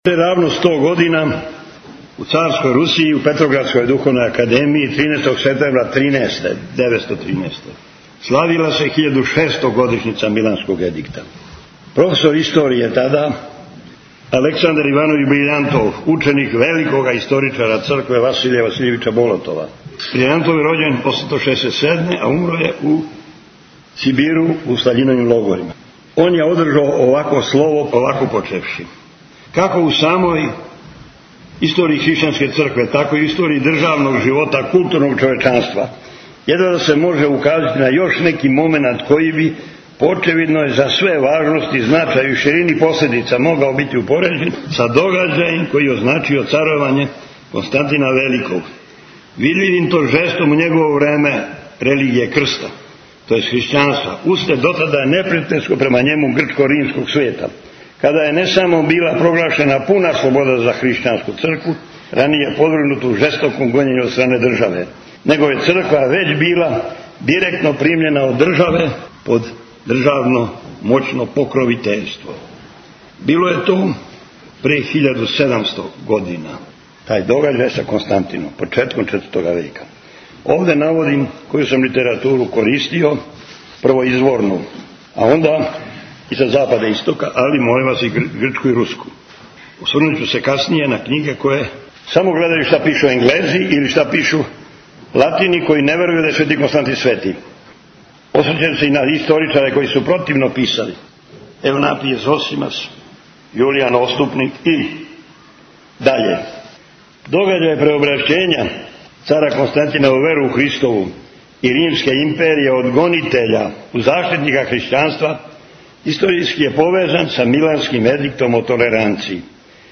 ГОСТОПРИМНИЦА – Визија цара Константина, одломак из предавања блаженопочившег владике Атанасија (Јевтића)